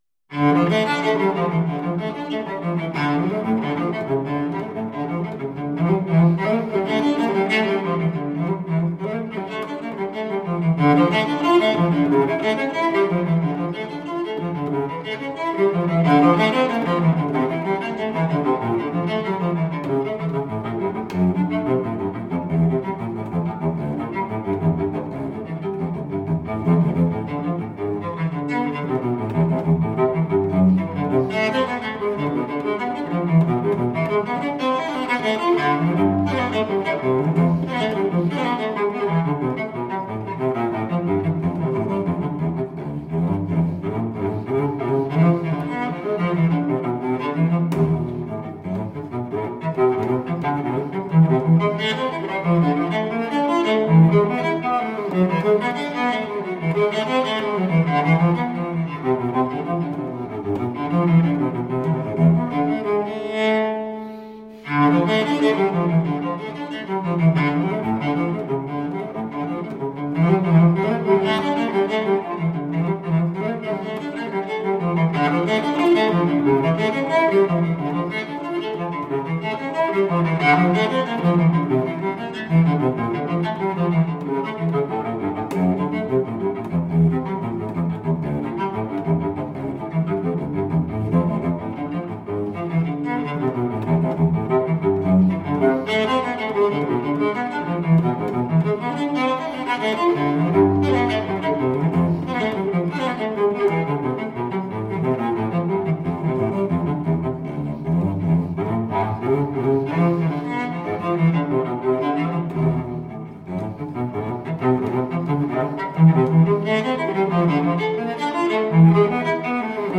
Classical, Baroque, Instrumental, Cello